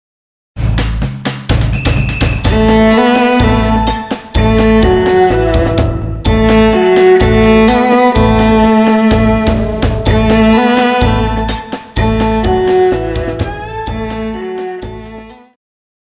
Pop
Viola
Orchestra
Instrumental
World Music,Fusion
Only backing